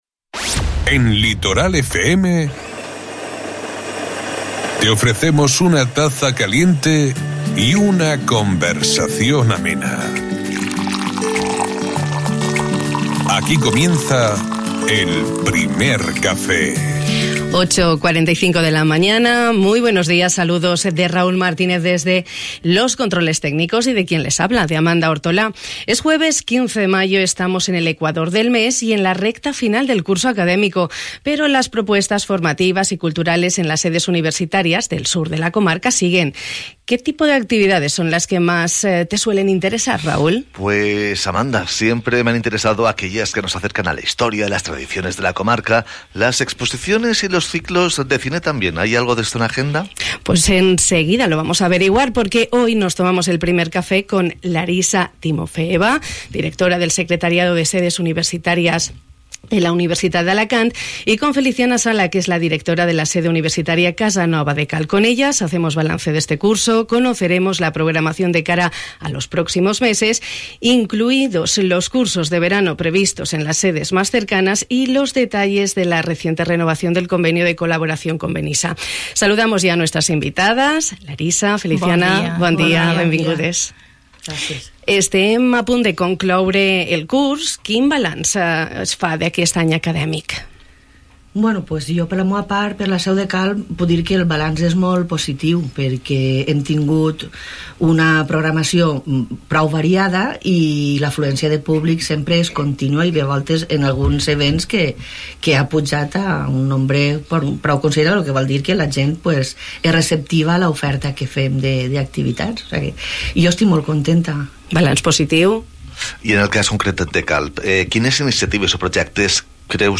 Alcanzamos el 15 de mayo, estamos en el ecuador del mes y en la recta final del curso académico, pero las propuestas formativas y culturales en las sedes universitarias continúan, como hemos podido comprobar en el Primer Café de esta mañana. Espacio radiofónico